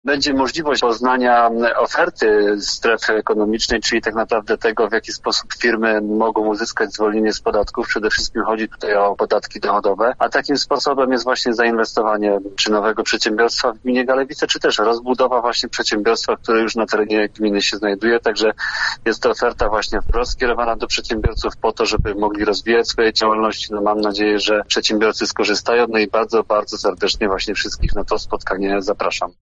– mówił wójt gminy Galewice, Piotr Kołodziej.